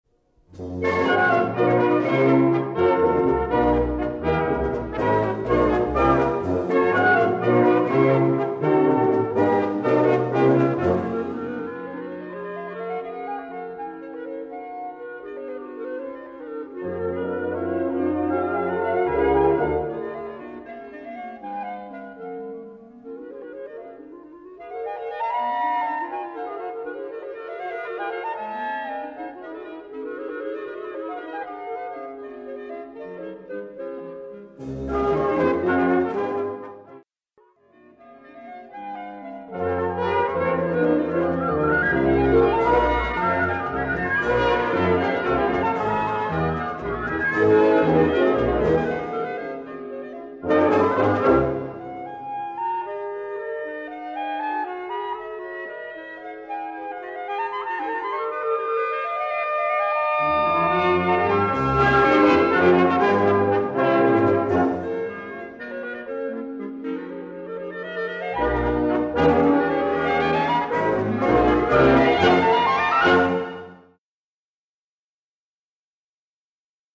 Gattung: Intermezzo für 4 Klarinetten und Blasorchester
Besetzung: Blasorchester